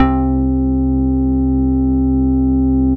Organ (1).wav